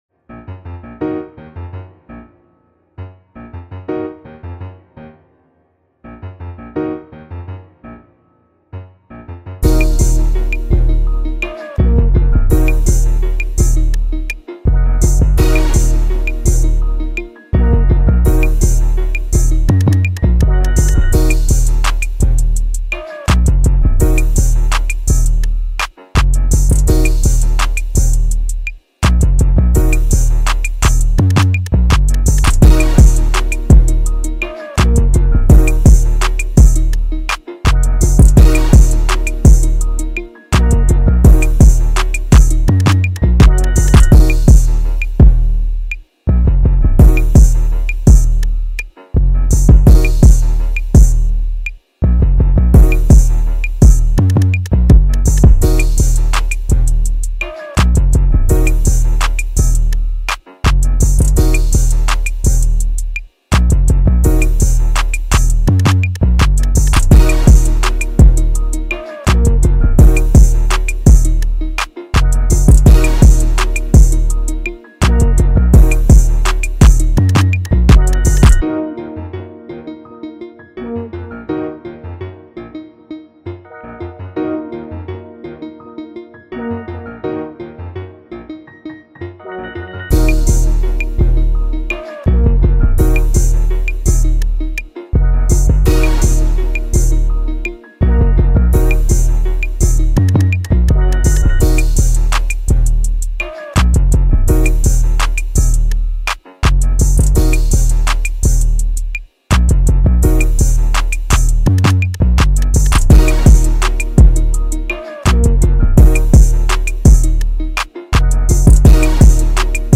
instrumental version
Hip-Hop Instrumentals